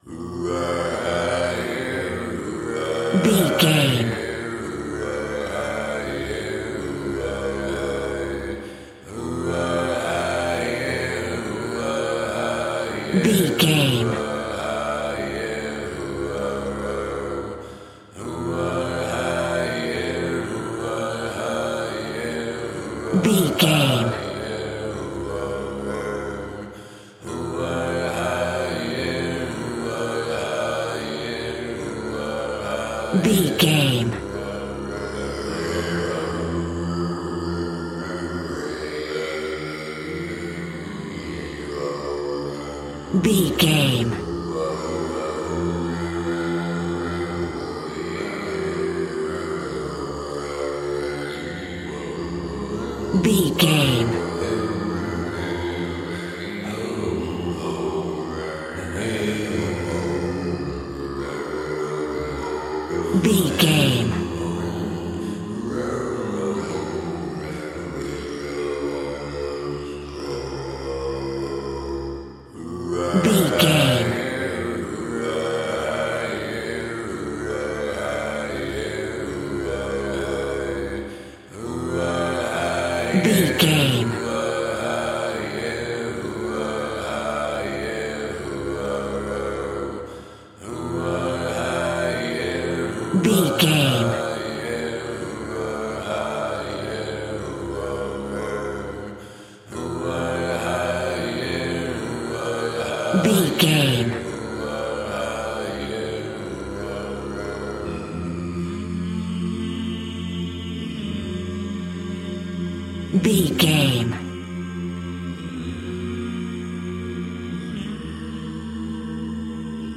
Aeolian/Minor
groovy
inspirational